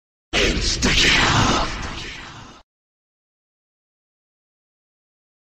call-of-duty-world-at-war-nazi-zombies-insta-kill-sound-effect.mp3